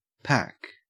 Ääntäminen
IPA : /pæk/ IPA : [pʰæk]